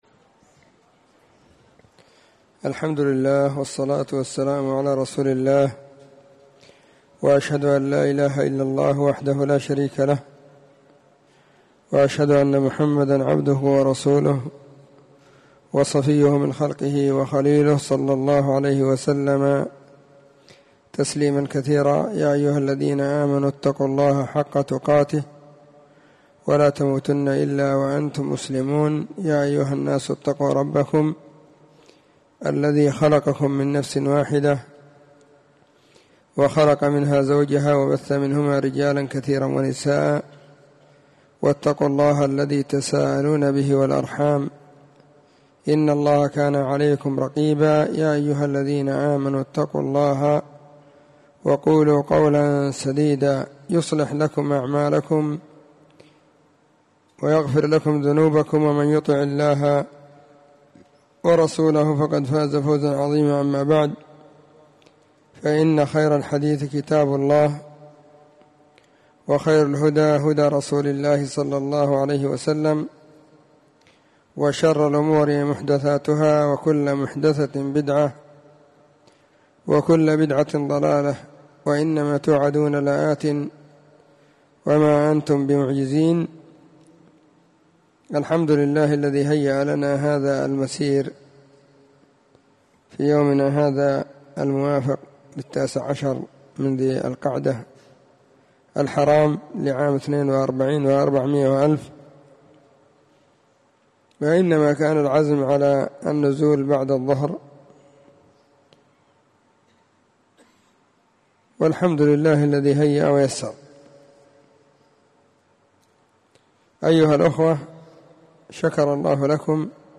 محاضرة بعنوان; *📚كلمات ونصائح قيمة.*
📢 مسجد الصحابة – بالغيضة – المهرة، اليمن حرسها •اللّـہ.